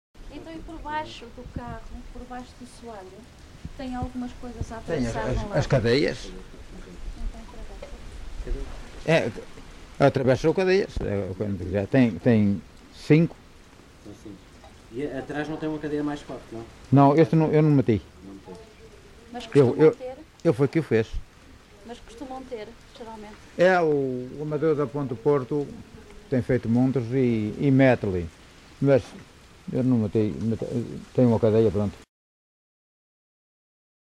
LocalidadeFiscal (Amares, Braga)